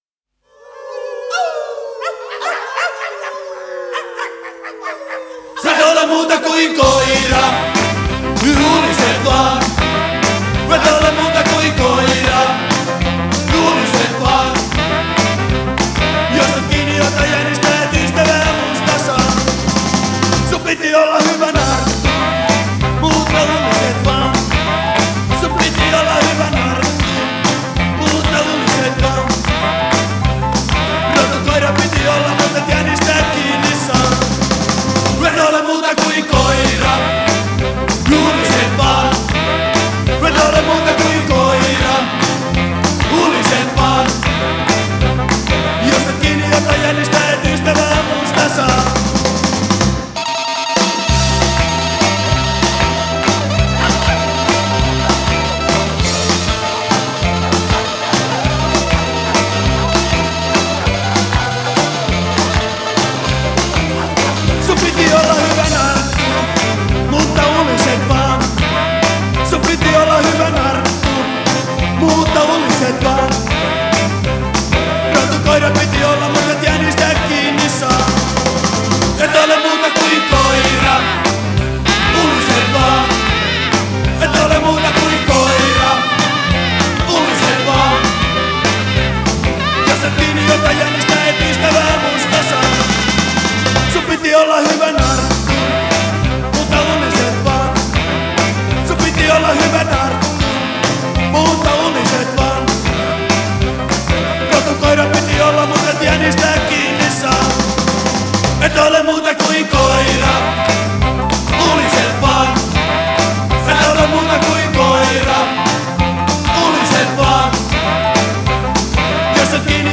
благодарю за поздравление и бодрую музыку